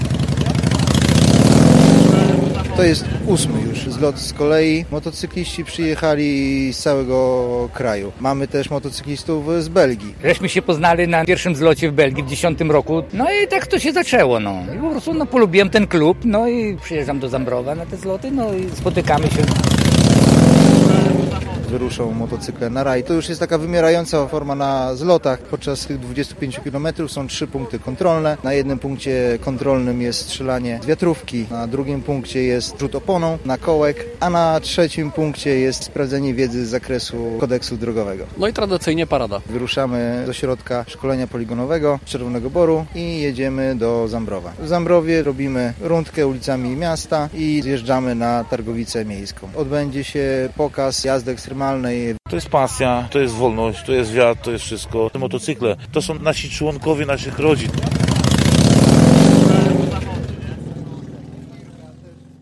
Zlot motocyklistów w Czerwonym Borze - relacja